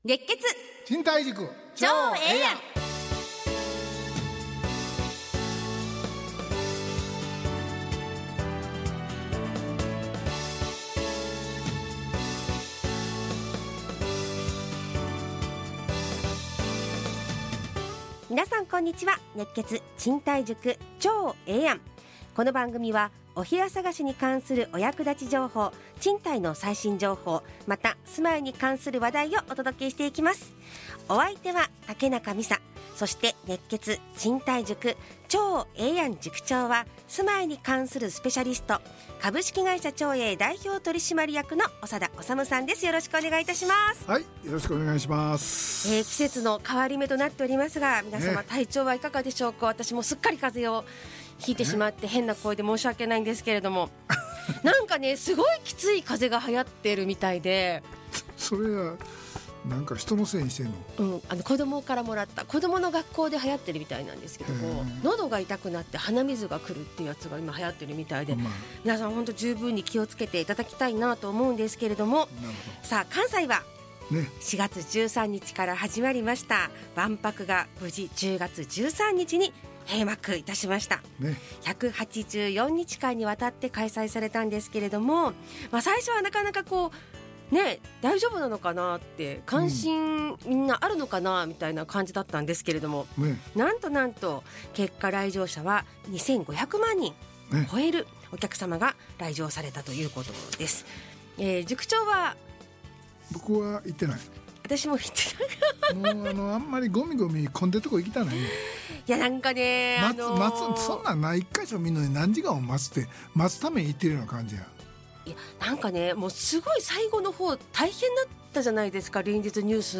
ラジオ放送 2025-10-20 熱血！賃貸塾ちょうええやん